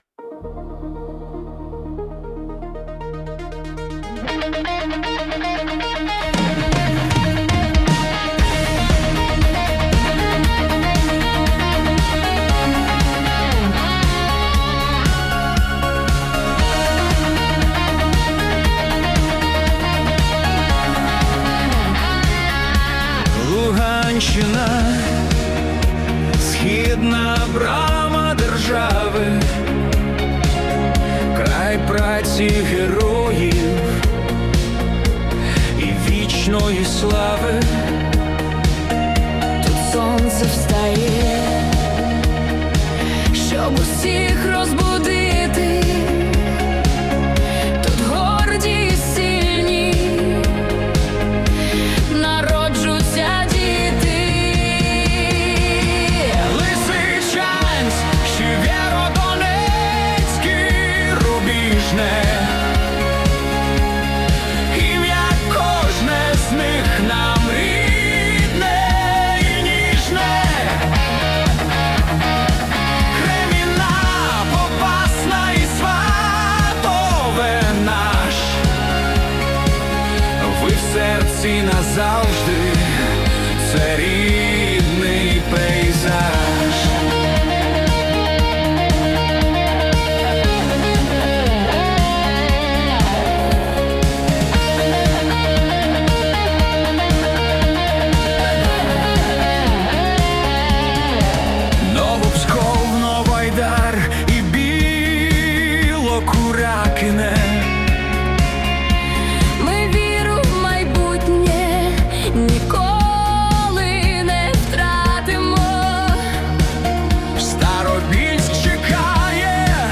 Ритм 120 ударів свободи